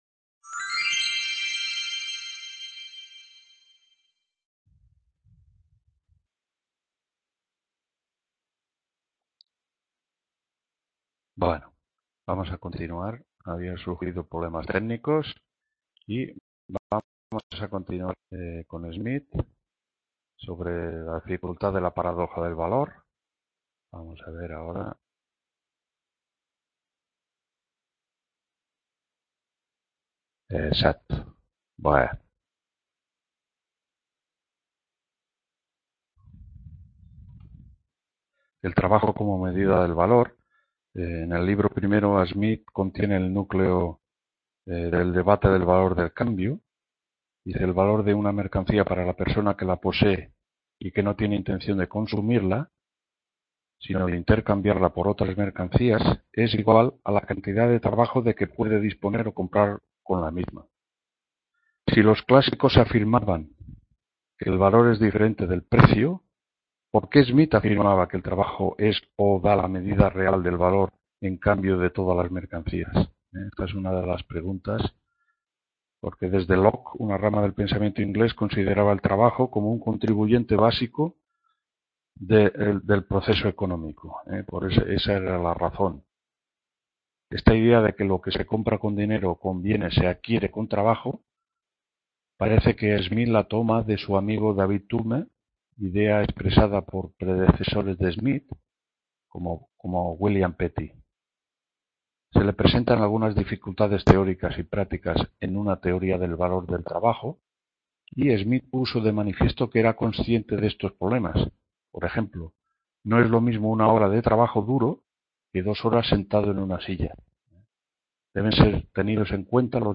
CONTINUACIÓN 2ª TUTORÍA HISTORIA DEL PENSAMIENTO…